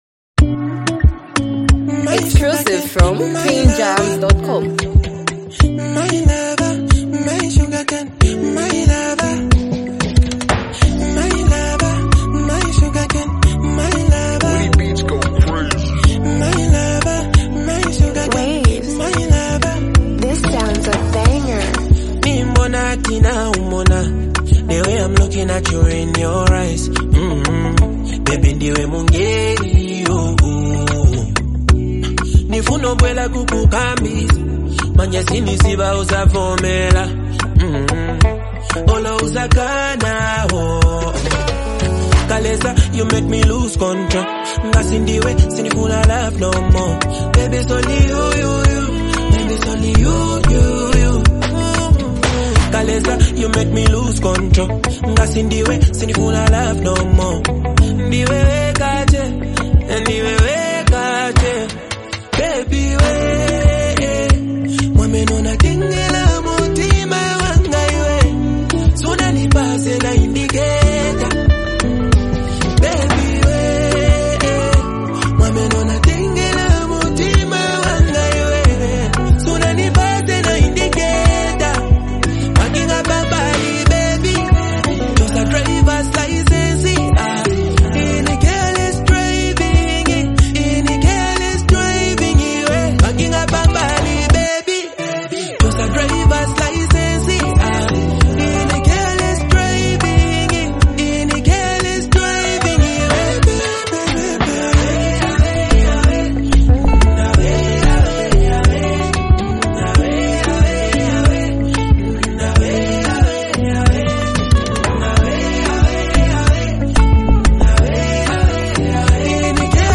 reflective and emotional song
smooth, soulful Zambian R&B sound
calm and expressive vocal delivery